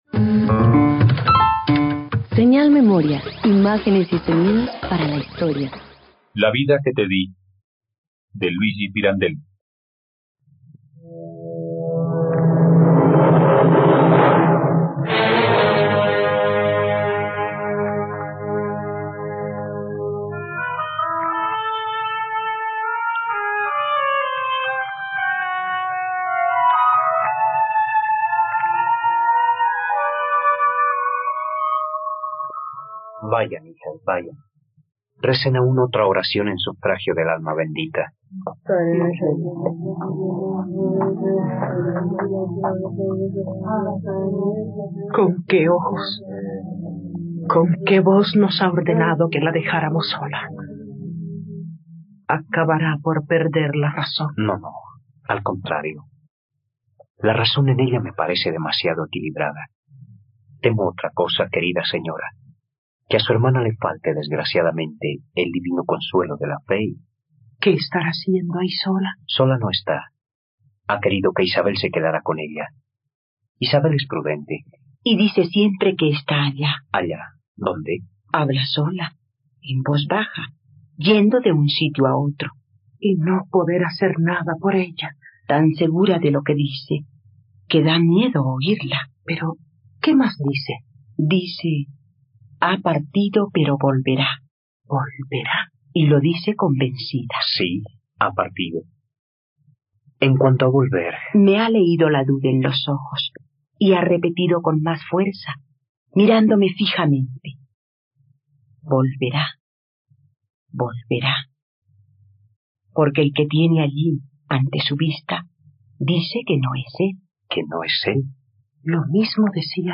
La vida que te di - Radioteatro dominical | RTVCPlay
..Radioteatro. Escucha la adaptación radiofónica de la obra ‘La vida que te di’ del autor italiano Luigi Pirandello en la plataforma de streaming RTVCPlay.